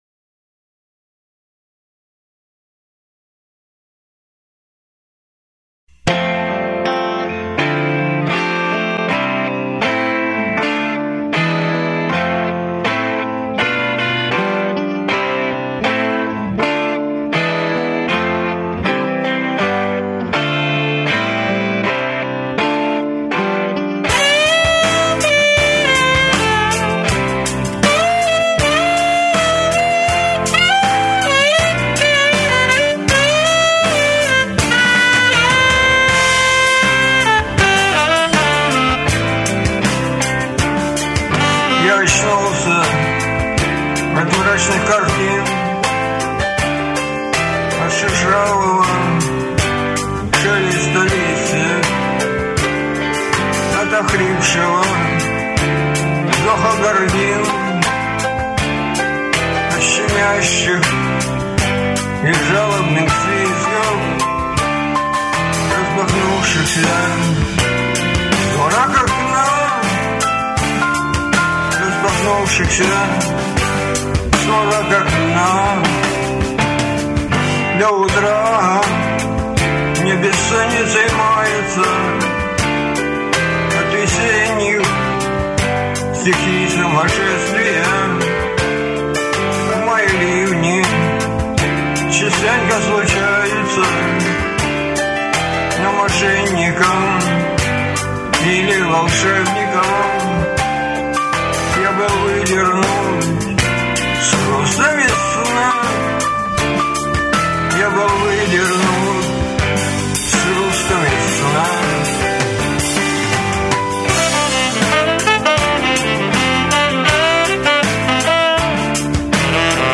Блюз (1232)